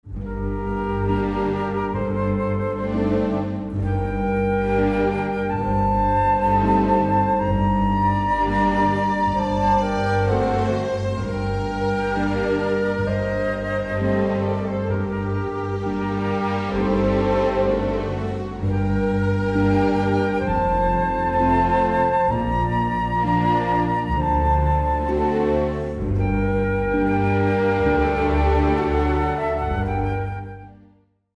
Chants d'adoration